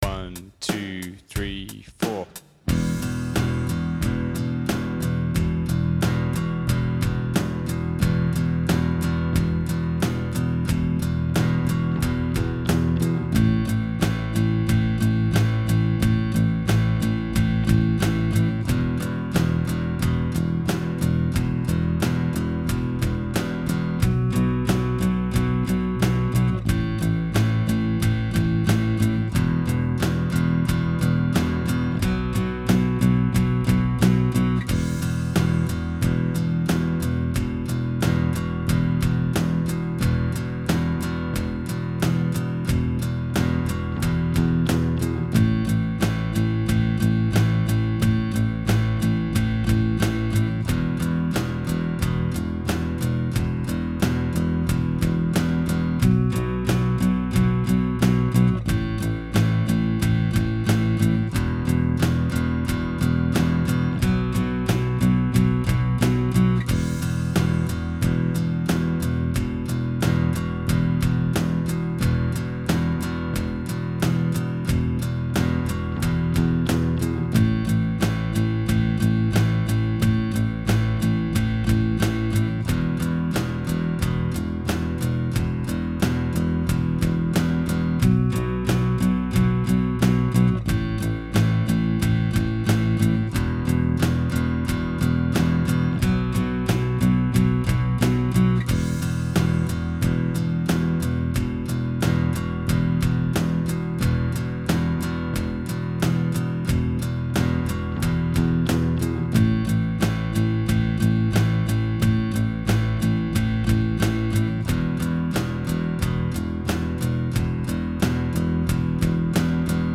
Completely Crazy Kid Backing Track | Download